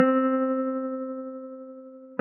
Rusty Guitar.wav